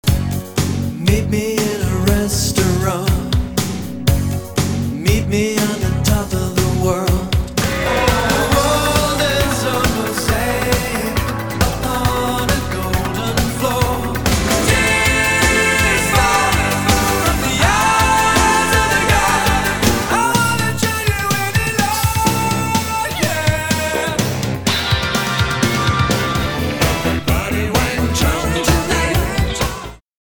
this is a largely joyful record.